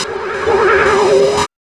4606R SYN-FX.wav